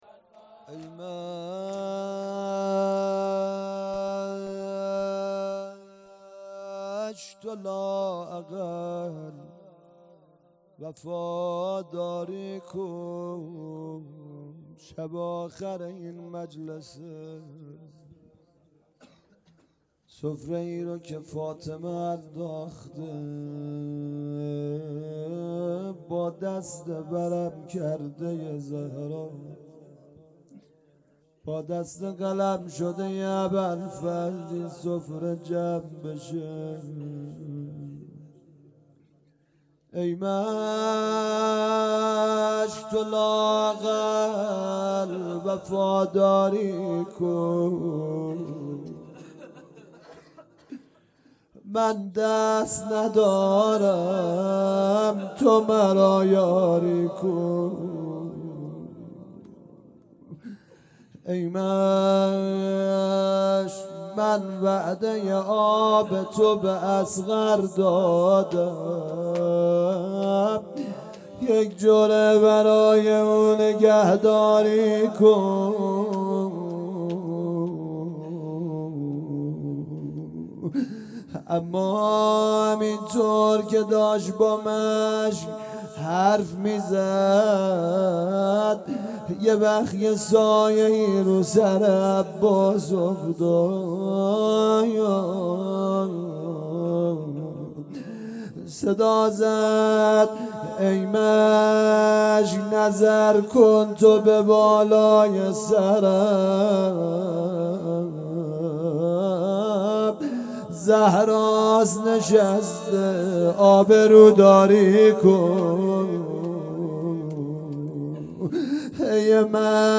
هيأت یاس علقمه سلام الله علیها
ای مشک تو لااقل وفاداری کن - روضه پایانی
شهادت حضرت فاطمه زهرا سلام الله علیها - شب سوم - فاطمیه دوم